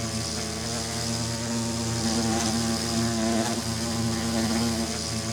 minecraft / sounds / mob / bee / loop4.ogg